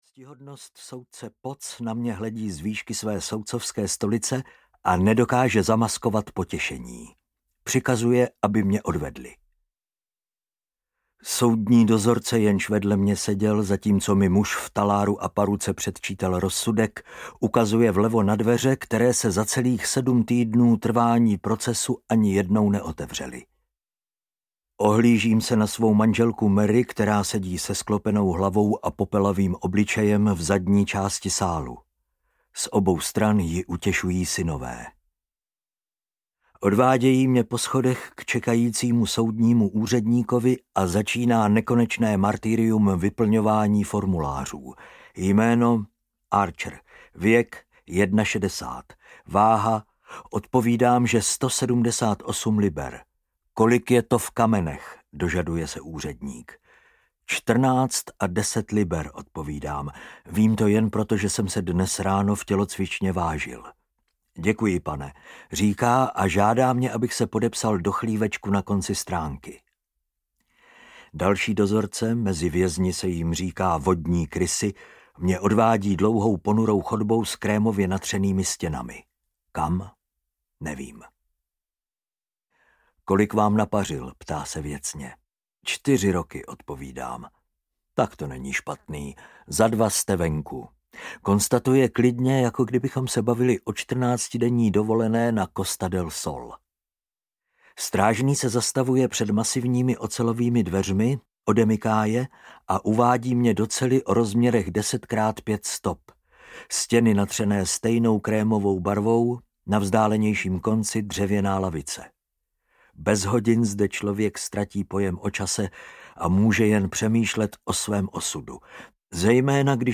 Audio knihaVězeňský deník I – Belmarsh: Peklo
Ukázka z knihy